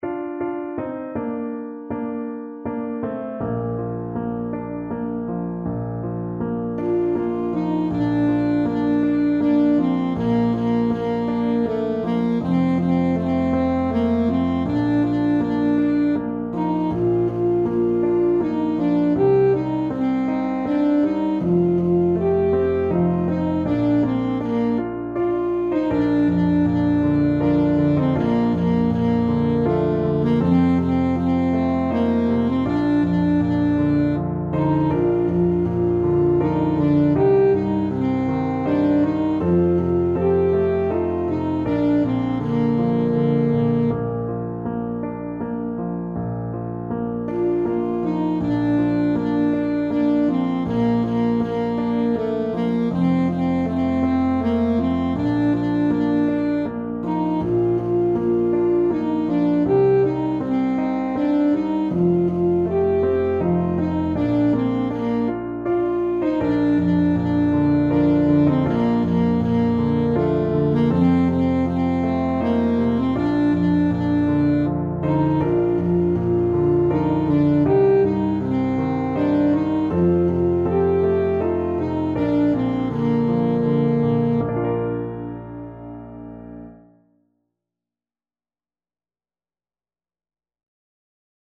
Alto Saxophone
Andante
3/4 (View more 3/4 Music)
Traditional (View more Traditional Saxophone Music)